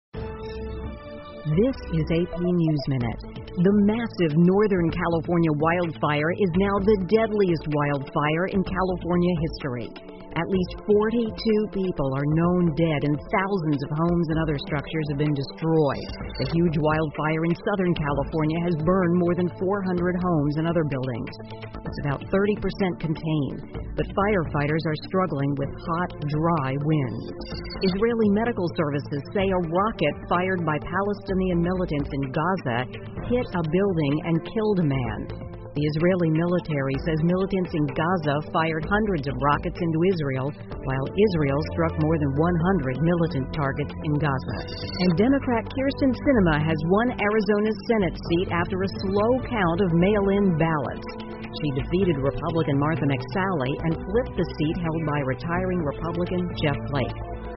美联社新闻一分钟 AP 加州北部野火导致42人丧生 听力文件下载—在线英语听力室